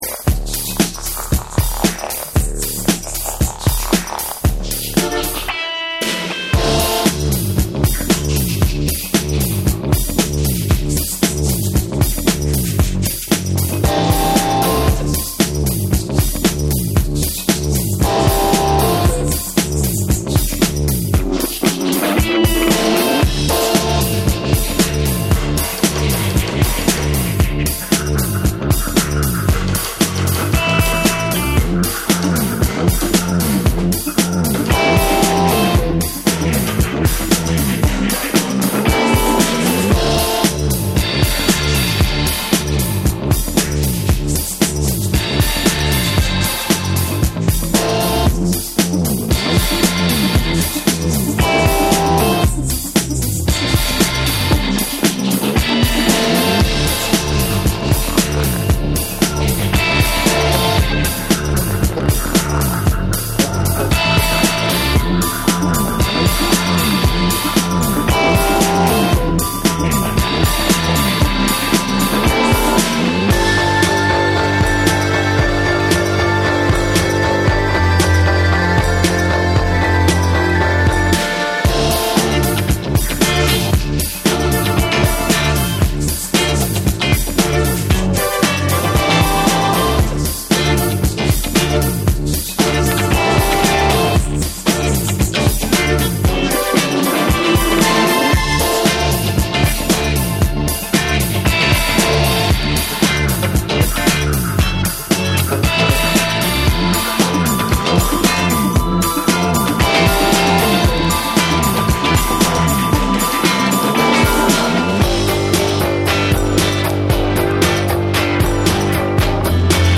BREAKBEATS / NEW WAVE & ROCK